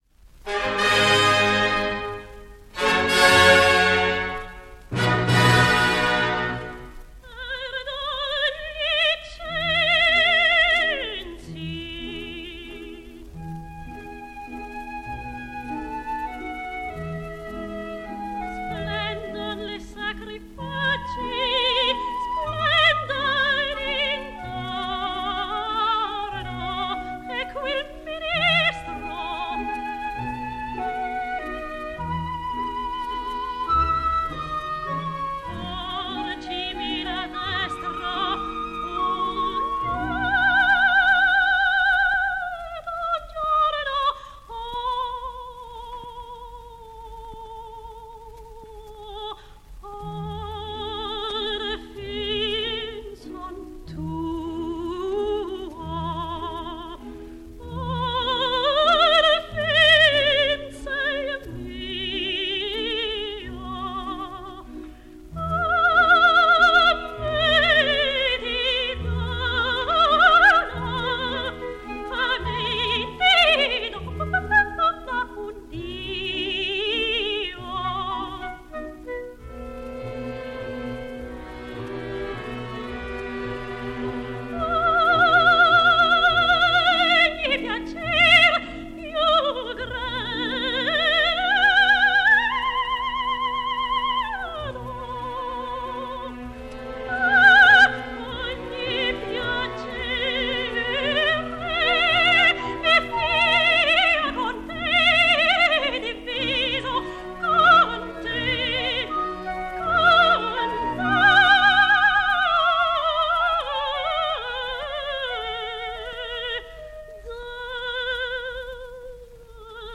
flûte solo